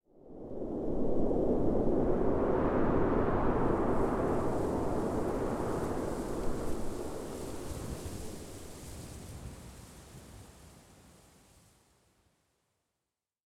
housewind09.ogg